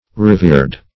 revered - definition of revered - synonyms, pronunciation, spelling from Free Dictionary